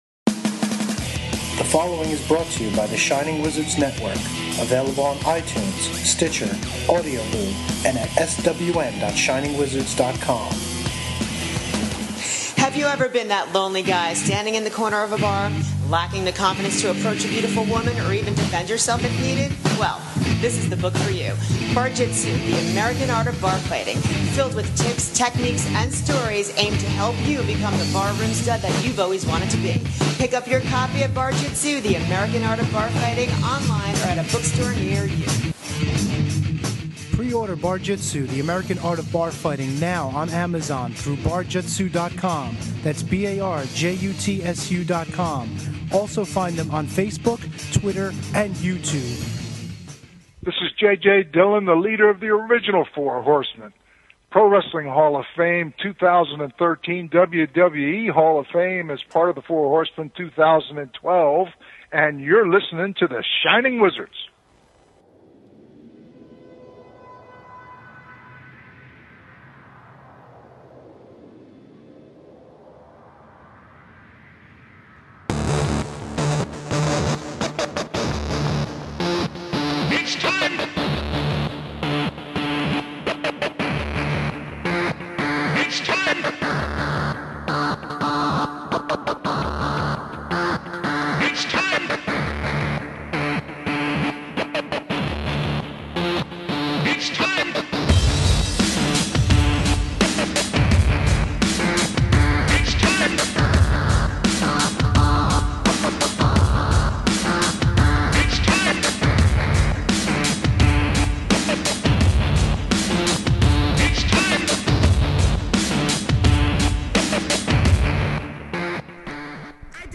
Then things get “Dangerous” when they speak to UFC Hall of Famer and WWE King of the Ring Ken Shamrock. And for some reason, there’s lots of mispronunciations of words.